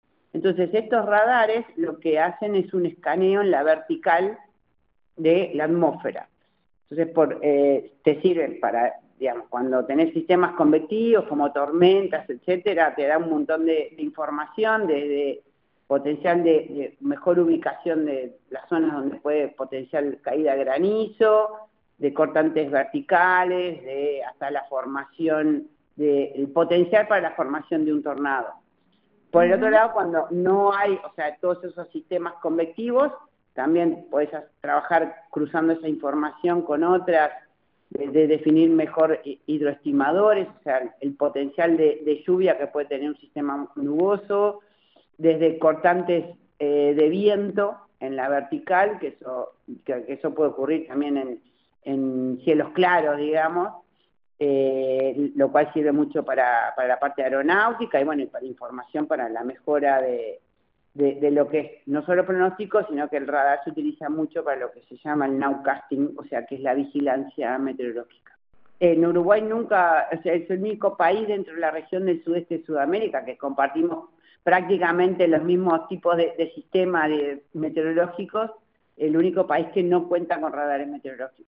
La presidenta del Instituto Uruguayo de Meteorología explicó para qué sirven exactamente estos radares.